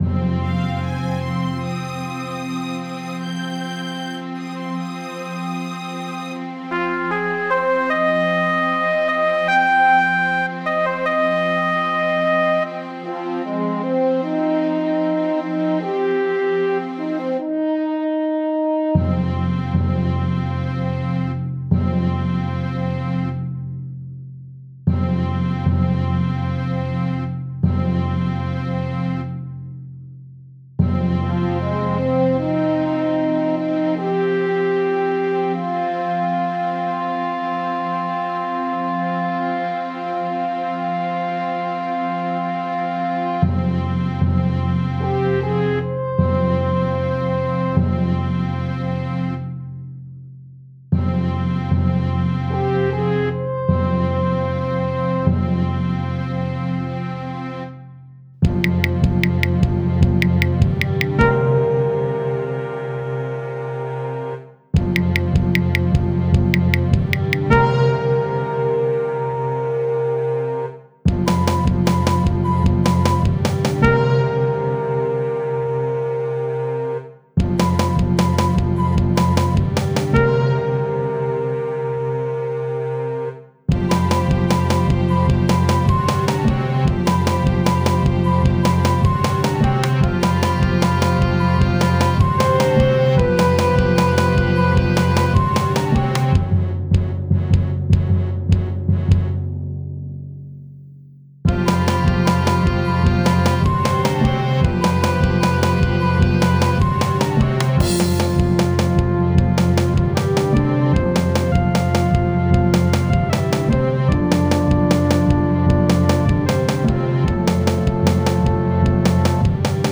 2022 Music only; no visuals, no video